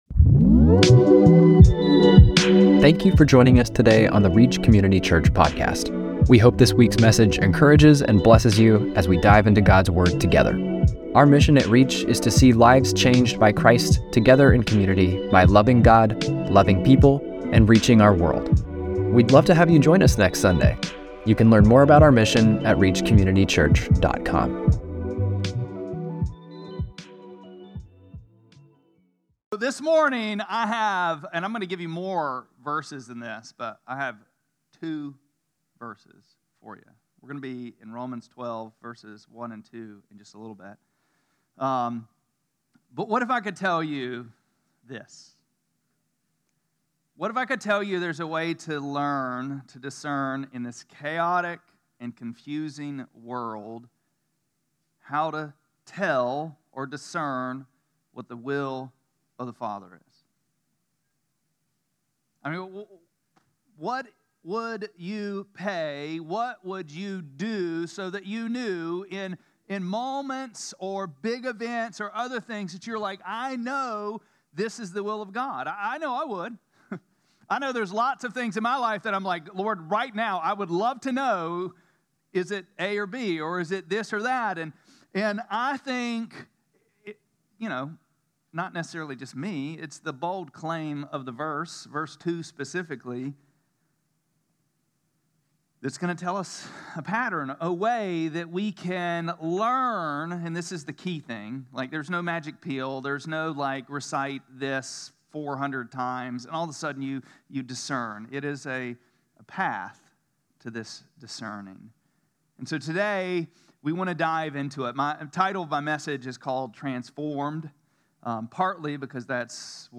8-10-25-Sermon.mp3